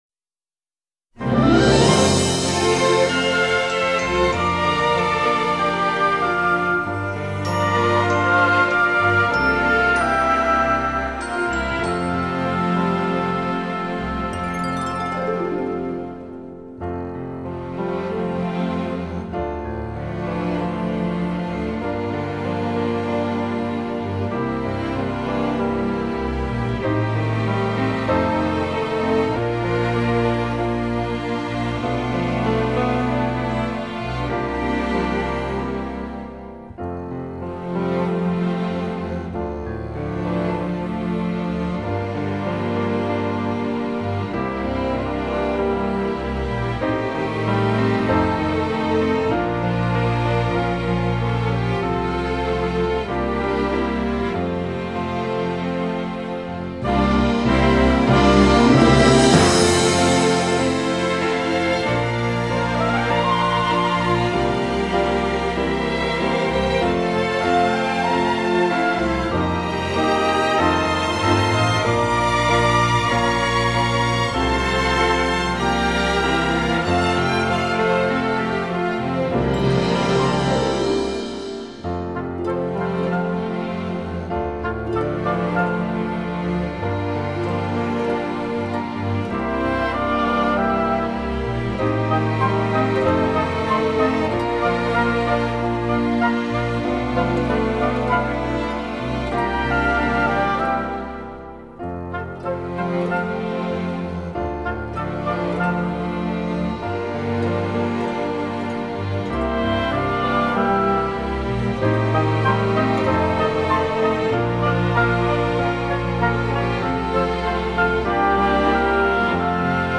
ミュージックバージョン
市歌（カラオケバージョン） (音声ファイル: 6.7MB)